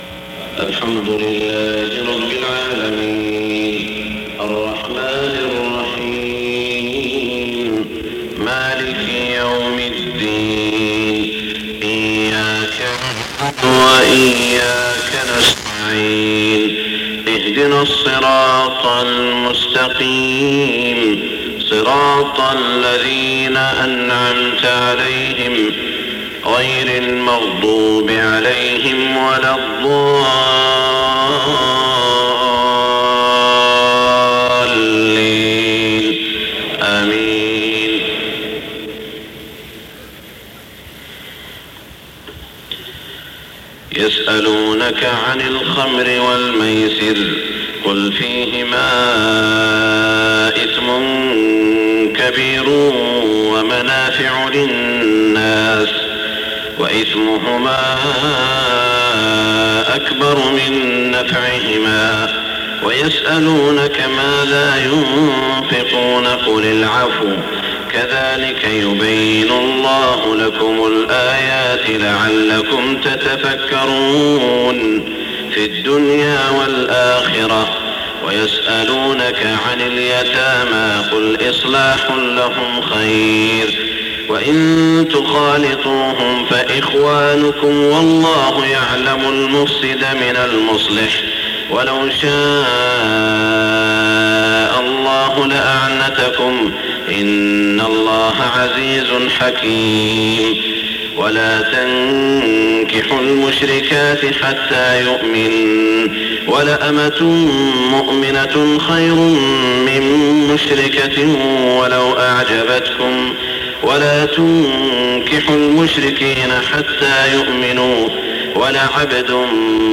صلاة الفجر 8-4-1426 من سورة البقرة > 1426 🕋 > الفروض - تلاوات الحرمين